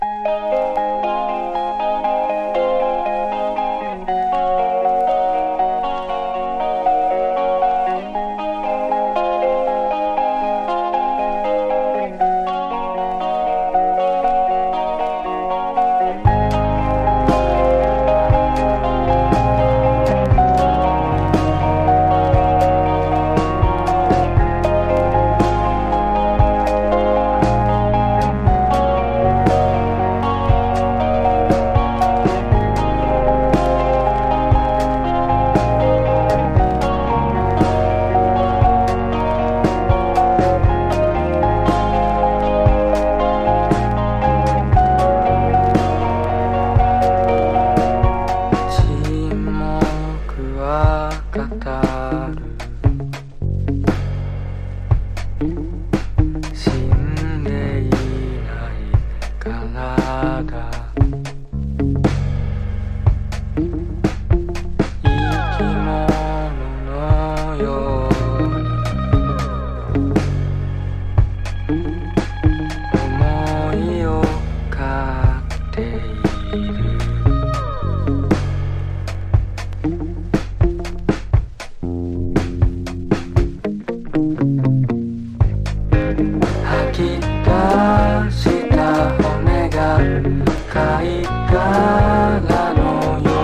淡々としたサイケデリアが滲む、詩情溢れる白昼夢ストレンジ・ポップ！